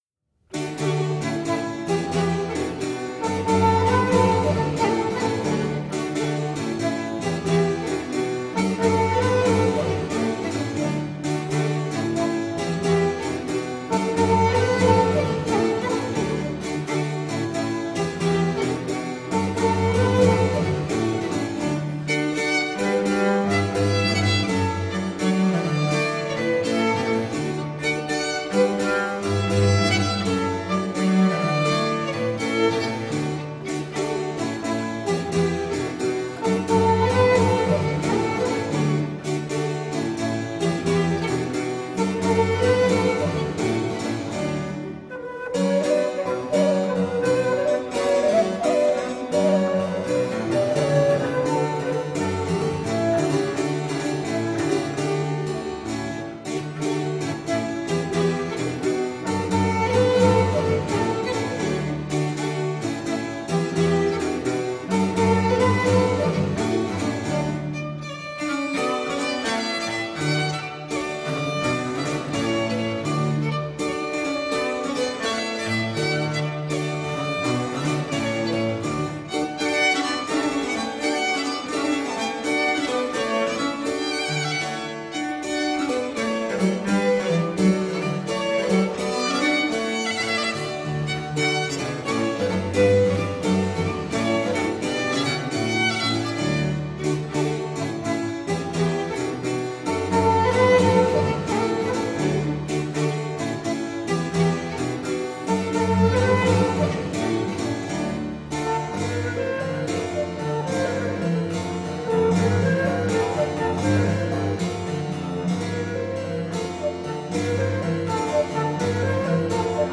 baroque
En revanche, il fut comme eux un maître de la musique sacrée, et rivalisa avec Rameau par sa musique pour clavecin et sa musique de chambre. C’est à ce dernier genre qu’appartiennent les danses suivantes, à comparer avec celles de Ravel.